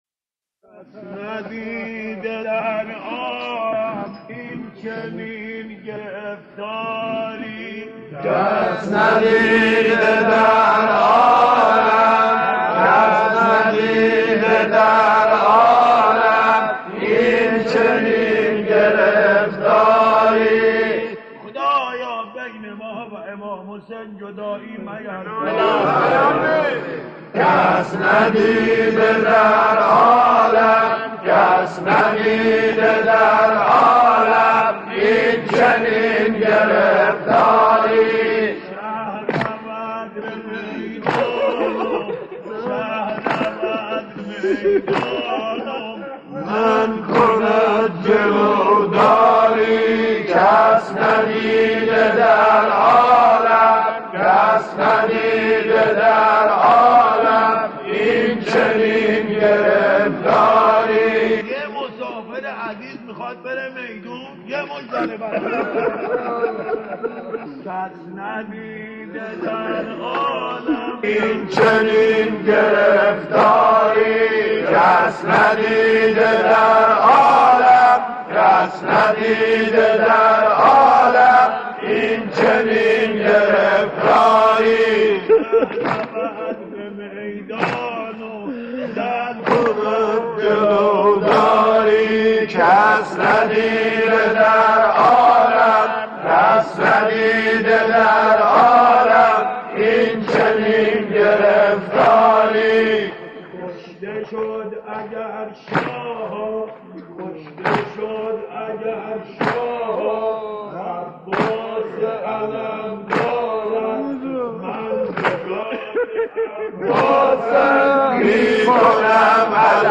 صوت | نغمه‌های عاشورایی از مداحان قدیم تهران